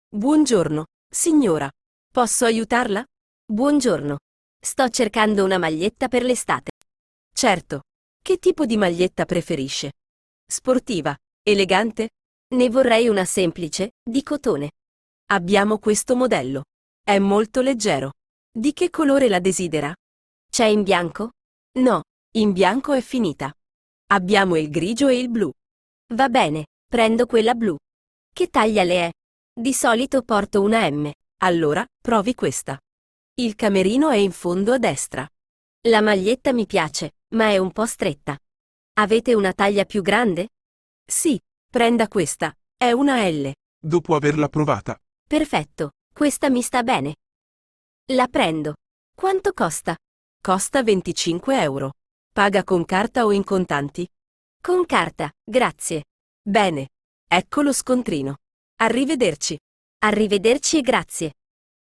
Dialoghi